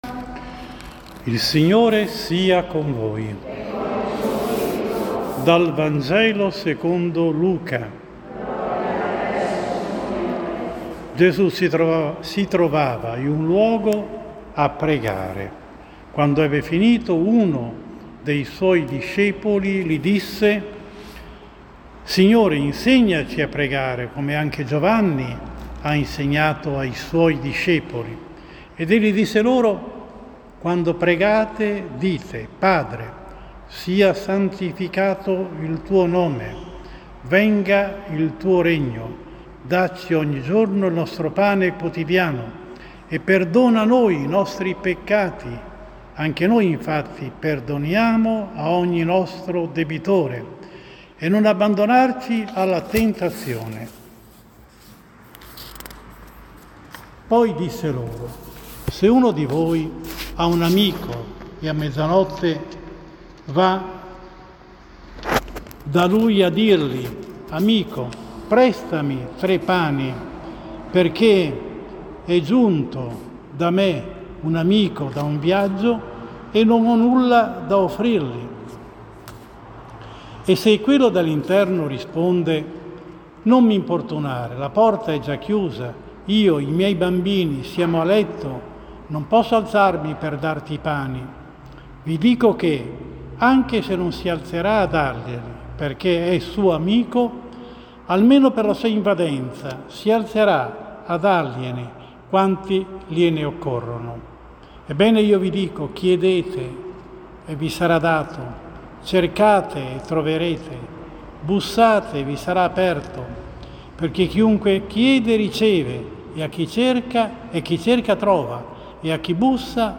24 Luglio 2022, XVII DOMENICA anno C: omelia